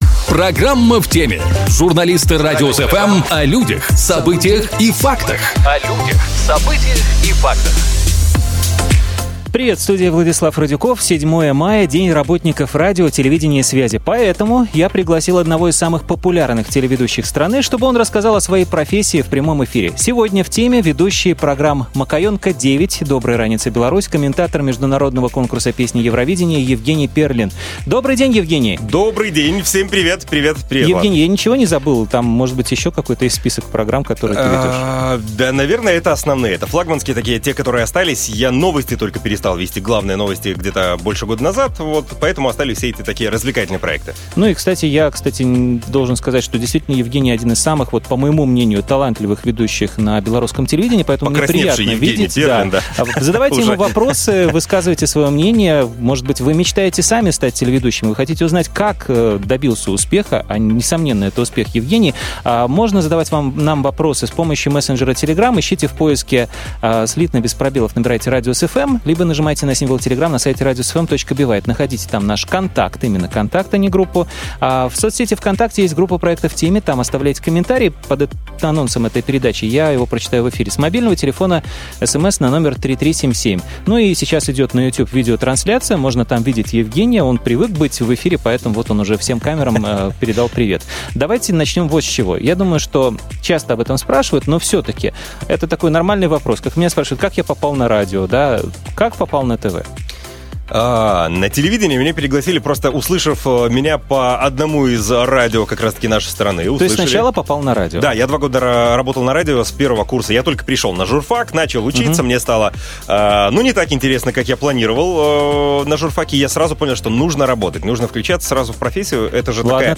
Мы пригласили одного из самых популярных телеведущих страны, чтобы он рассказал в прямом эфире о своей профессии.